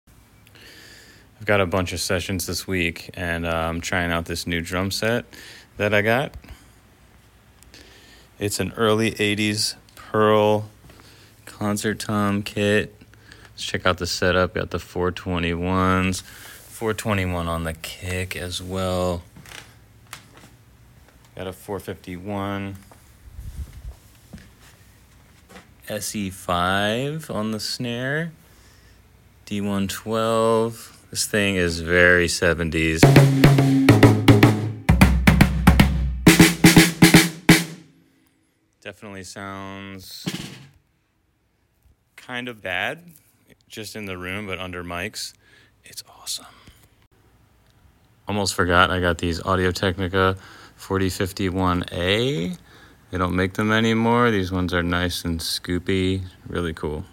Fun 70’s vibe drum sessions sound effects free download